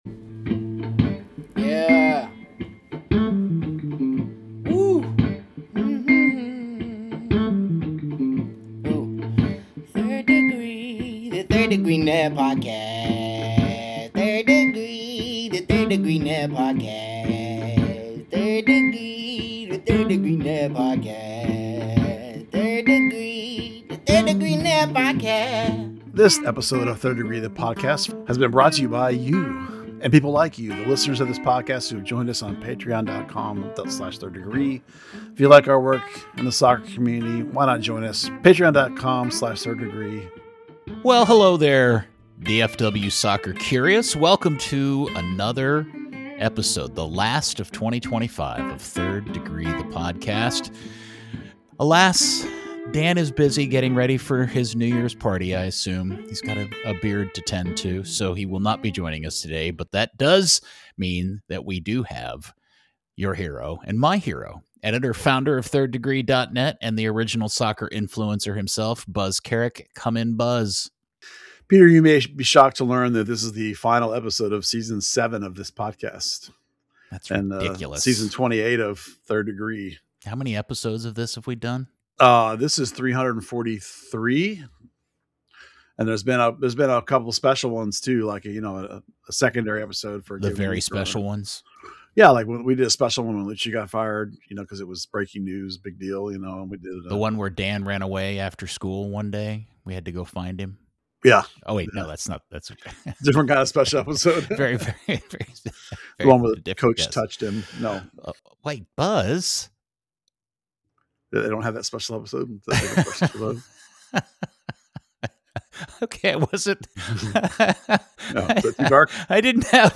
FCD also made some draft picks, and the duo will tell you what you need to know.